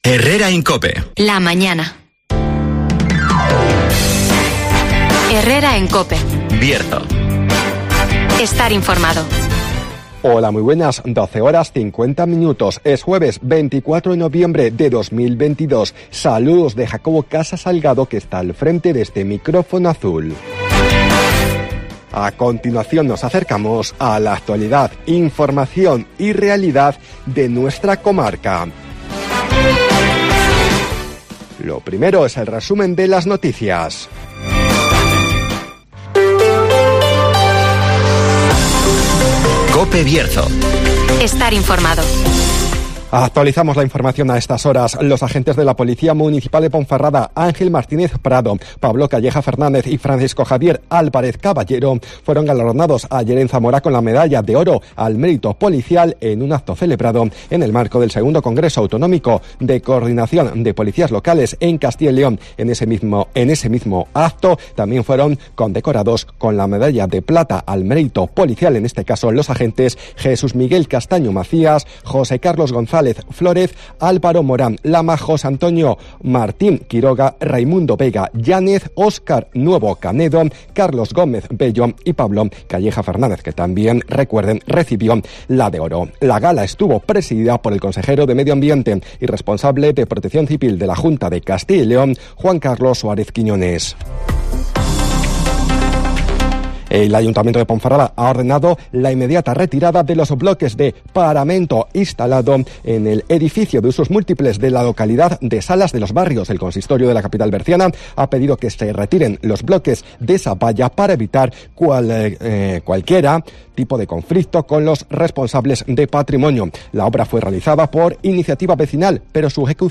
AUDIO: Resumen de las noticias, el tiempo y la agenda